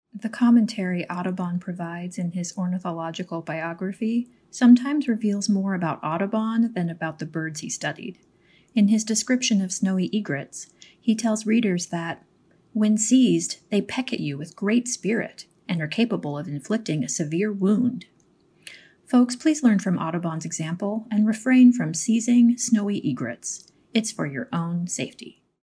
Listen to a curator talk about this work.